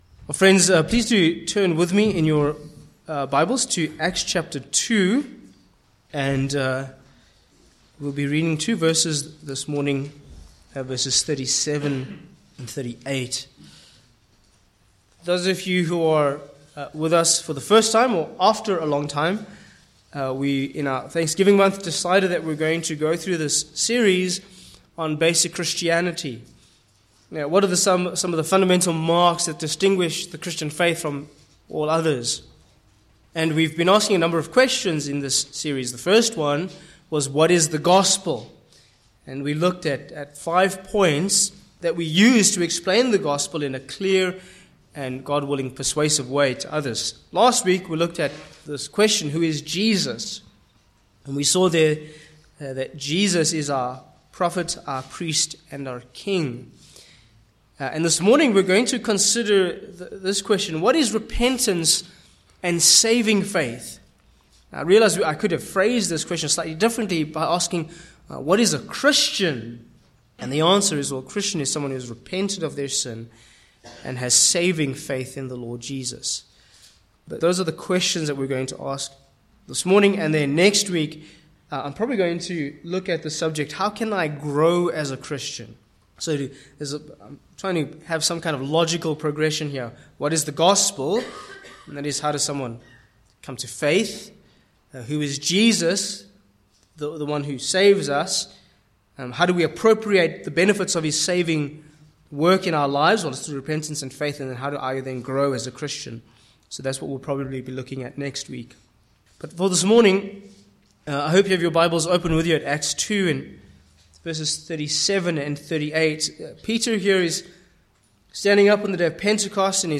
Passage: Acts 2:37-38 Sermon points: 1.
Acts 2:37-38 Service Type: Morning Passage